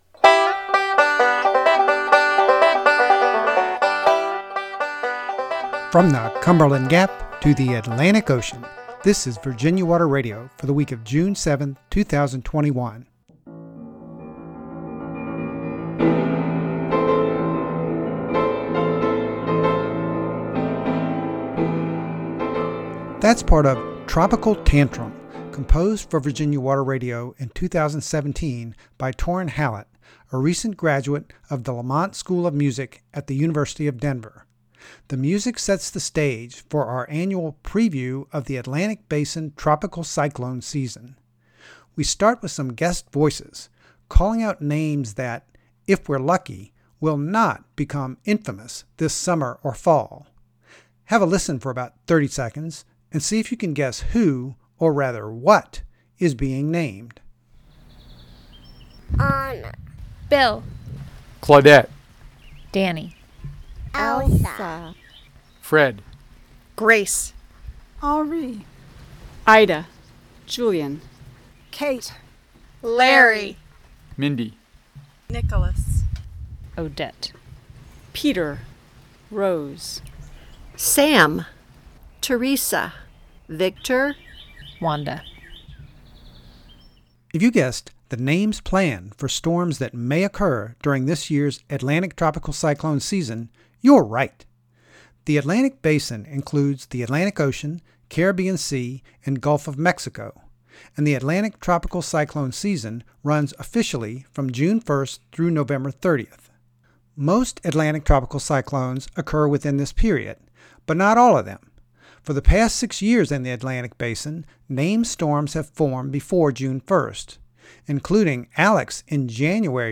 Thanks very much to Blacksburg friends who recorded the planned tropical cyclone names.